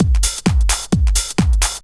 Index of /90_sSampleCDs/Ueberschall - Techno Trance Essentials/02-29 DRUMLOOPS/TE06-09.LOOP-TRANCE/TE08.LOOP-TRANCE3